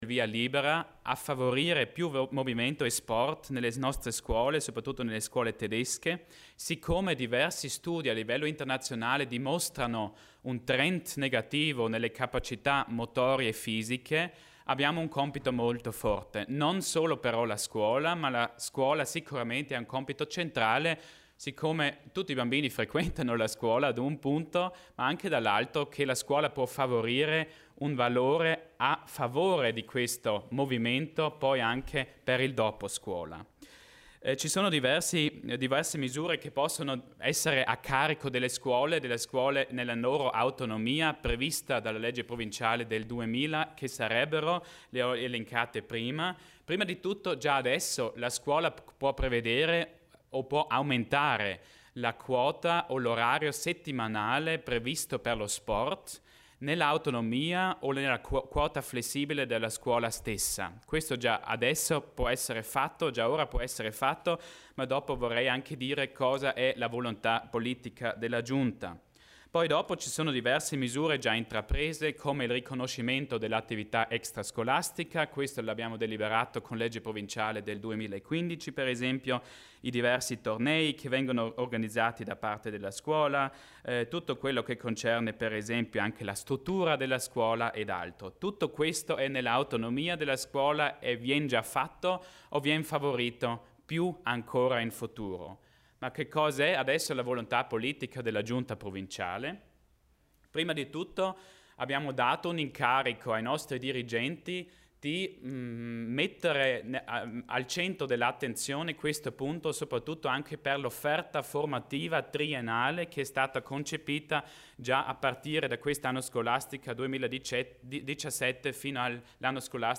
L'Assessore Achammer spiega le novità in ambito scolastico